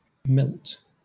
Ääntäminen
Southern England: IPA : /mɪlt/